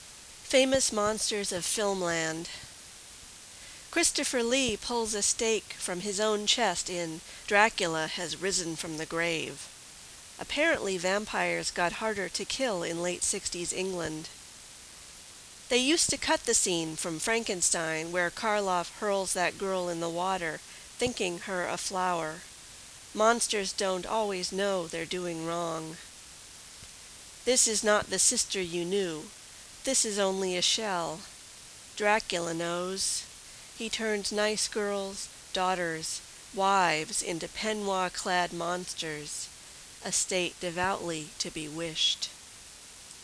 2008 Halloween Poetry Reading